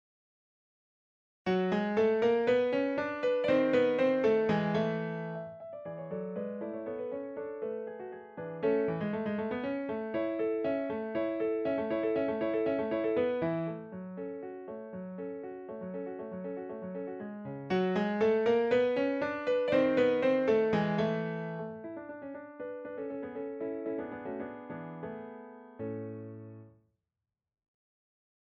Kafka page 270 - Allegro pour piano.
Petit allegro pour piano, qui rappelle le "Lustig-Traurig".
Kafka_Pagina270_AllegroPerPianoforte.mp3